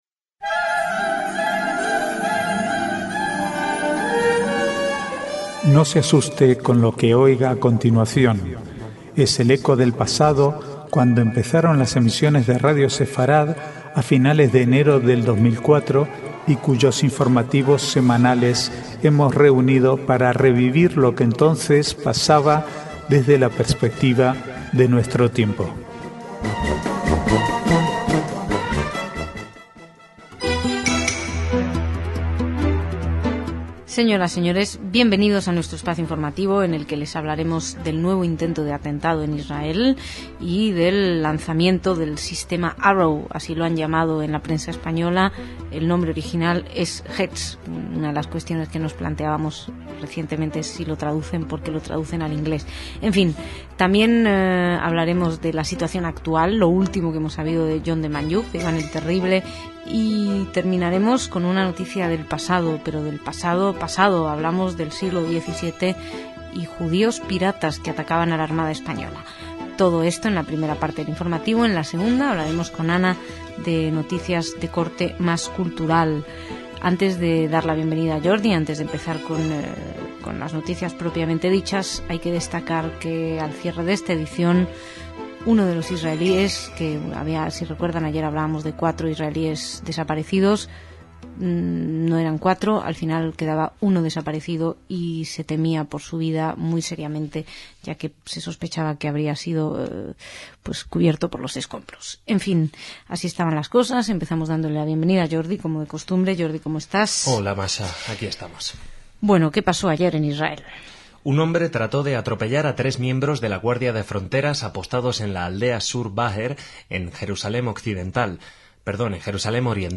Archivo de noticias del 8 al 14/4/2009